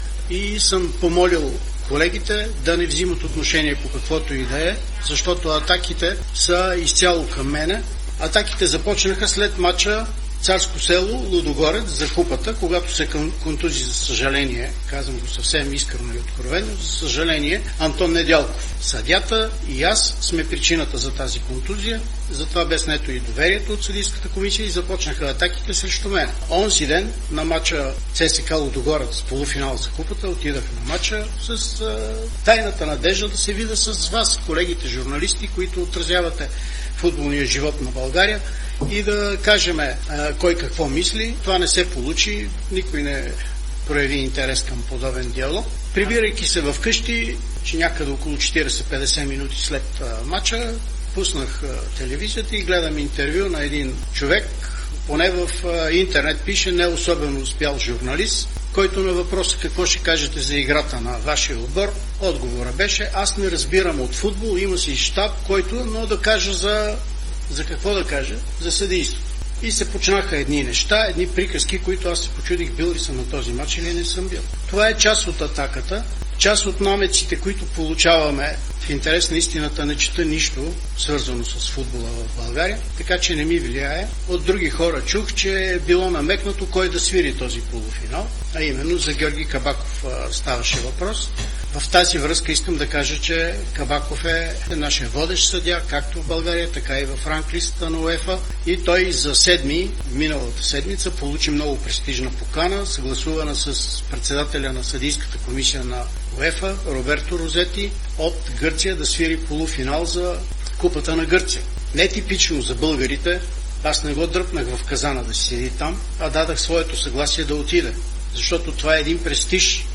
Той бе гост на "Студио Дерби", като бе категоричен, че атаките са изцяло срещу него и са започнали след контузията на защитника на Лудогорец Антон Недялков в мача с Царско село за Купата.